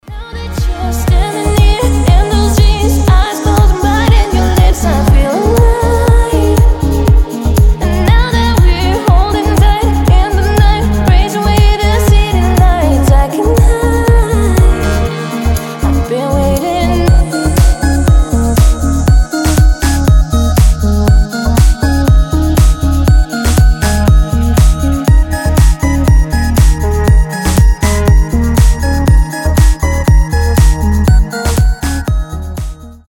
deep house
атмосферные
Electronic
чувственные
deep progressive